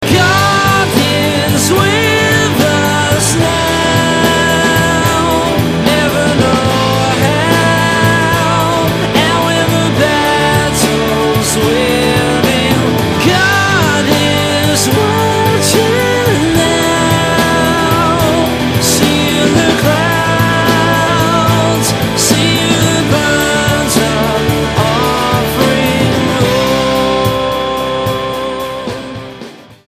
Devon-based indie rockers
Style: Rock